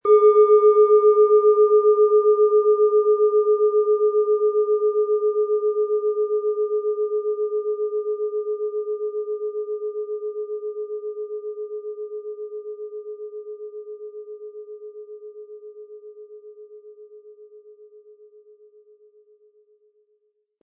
Planetenton 1
Planetenschale® Neue Ideen bekommen & Altes aufgeben mit Uranus, Ø 13,5 cm inkl. Klöppel
Um den Originalton der Schale anzuhören, gehen Sie bitte zu unserer Klangaufnahme unter dem Produktbild.
HerstellungIn Handarbeit getrieben
MaterialBronze